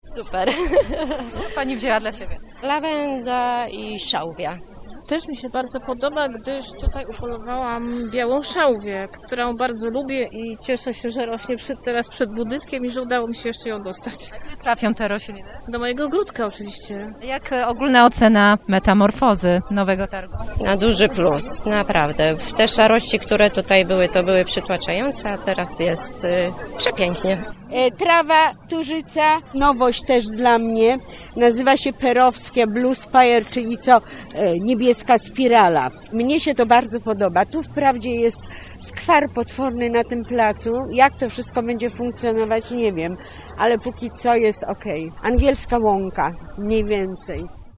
O zdanie zapytaliśmy wrocławian, którzy przyszli na otwarcie.
03_sonda.mp3